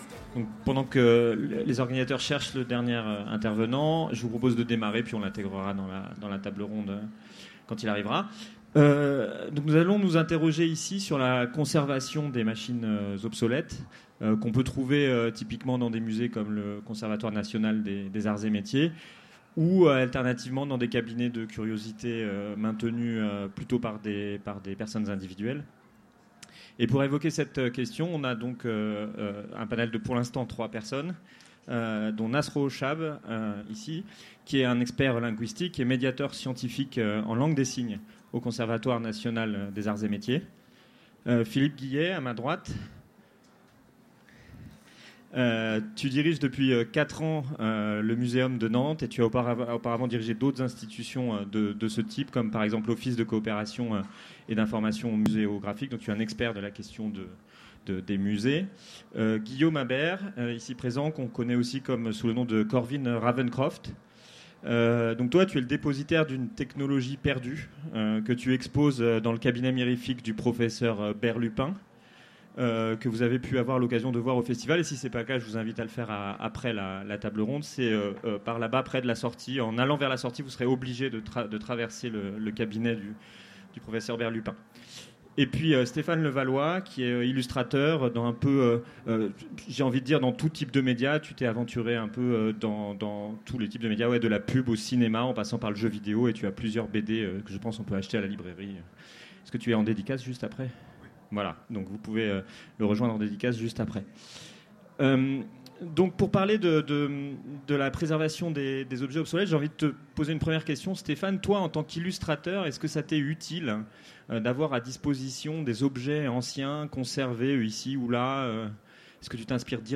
Conférence Les corps célestes enregistrée aux Utopiales 2018